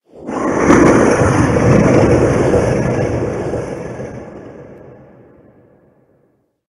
Cri de Torgamord Gigamax dans Pokémon HOME.
Cri_0834_Gigamax_HOME.ogg